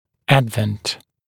[‘ædvənt][‘эдвэнт]приход, пришествие, наступление